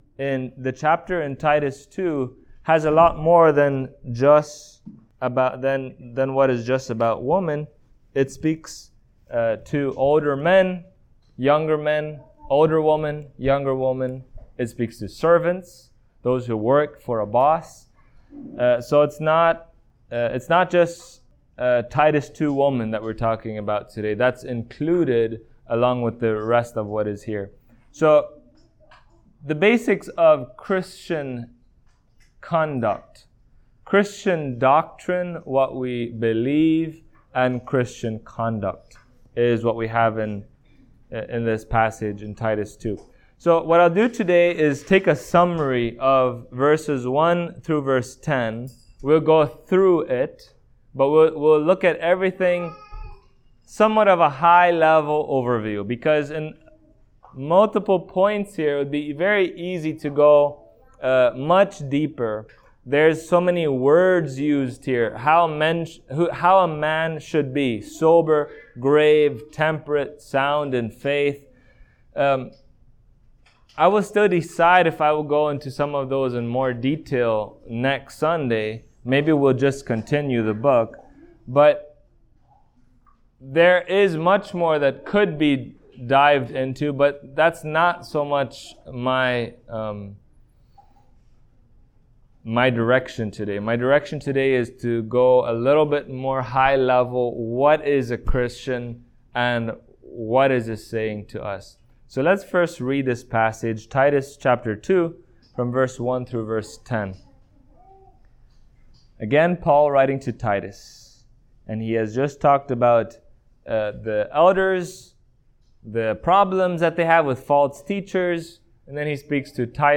Titus Passage: Titus 2:1-10 Service Type: Sunday Morning Topics